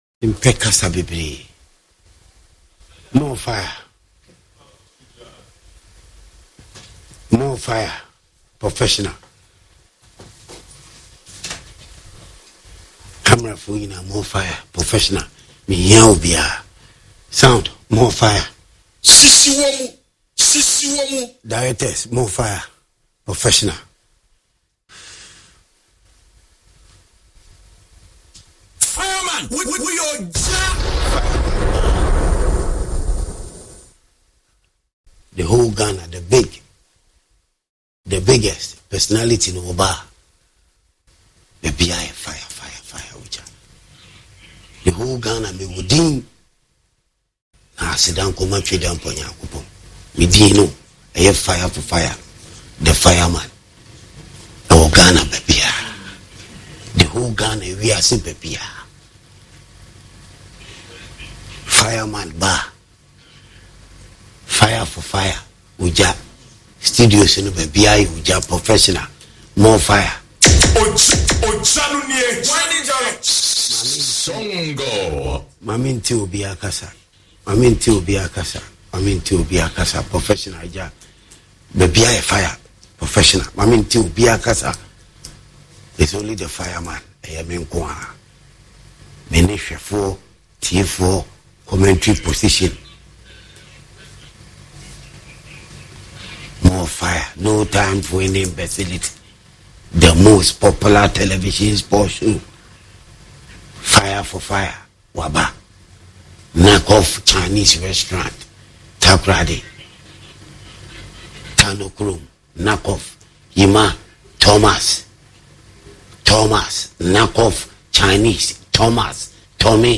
Sports monologue show